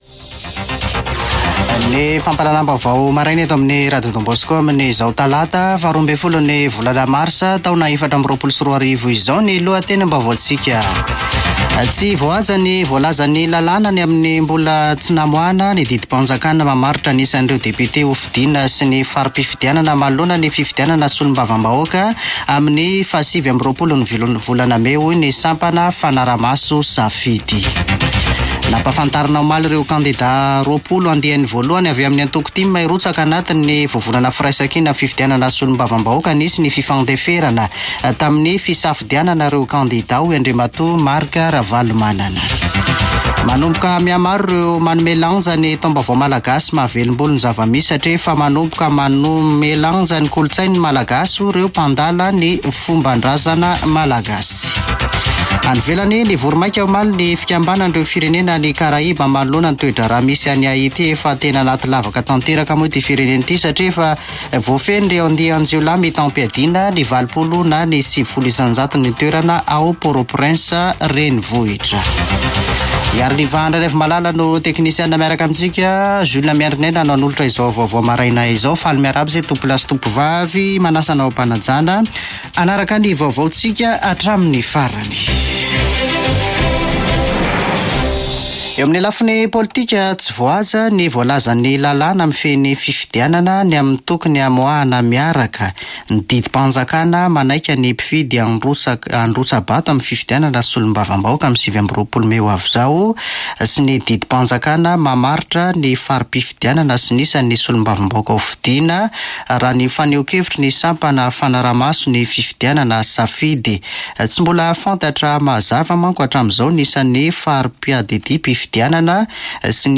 [Vaovao maraina] Talata 12 marsa 2024